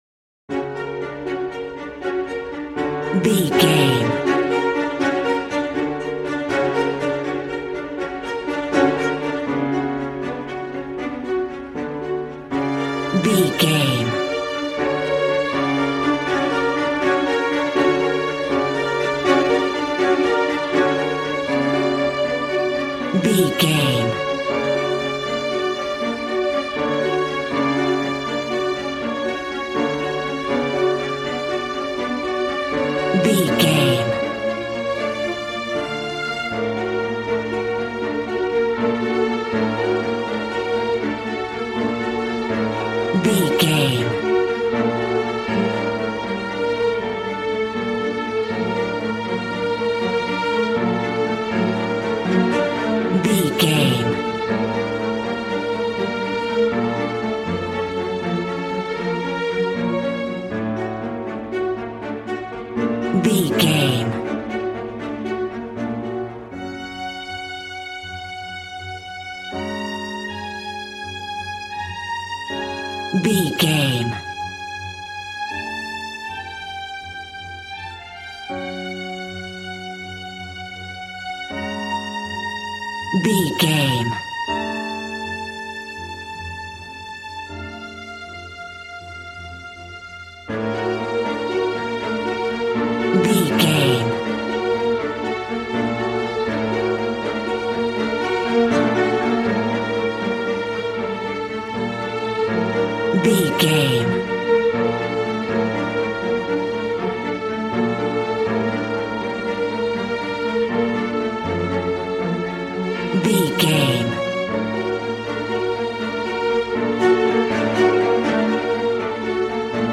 Regal and romantic, a classy piece of classical music.
Aeolian/Minor
B♭
regal
strings
brass